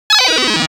mixkit-arcade-fast-game-over-233.wav